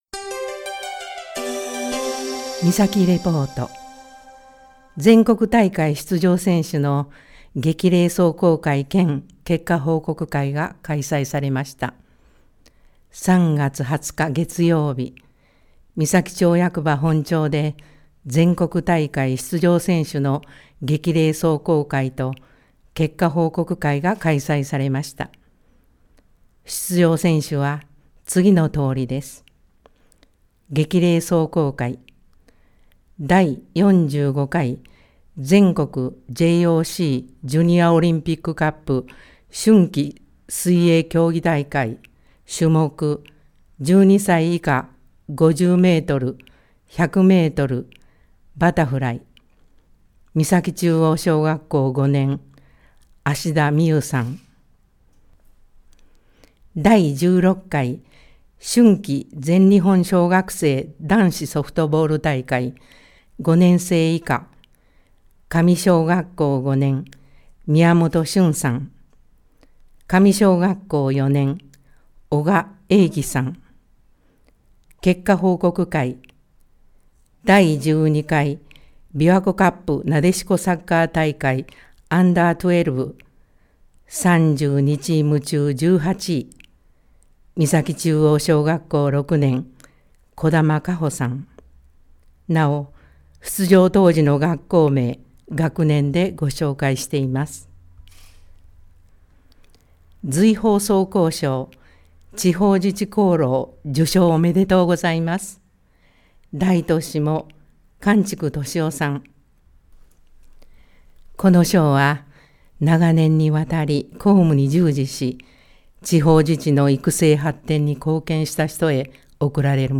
広報誌の一部を読み上げています。